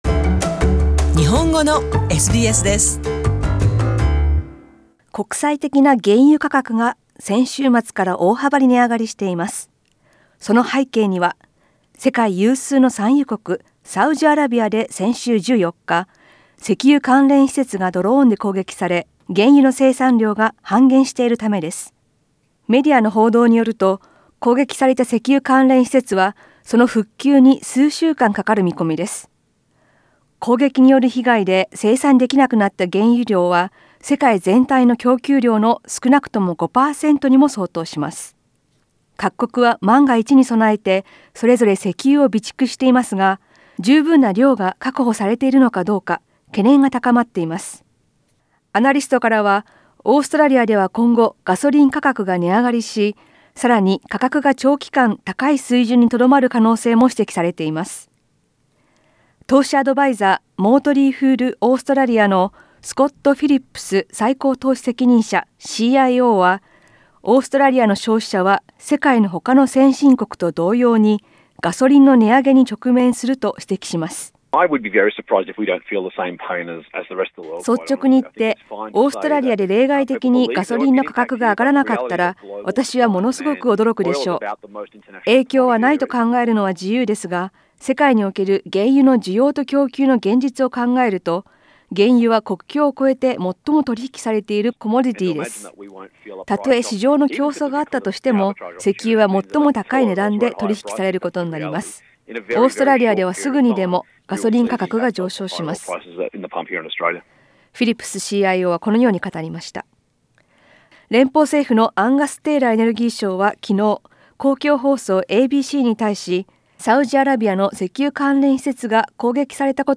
SKIP ADVERTISEMENT 詳しくは写真をクリックして音声リポートをどうぞ。